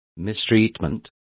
Complete with pronunciation of the translation of mistreatment.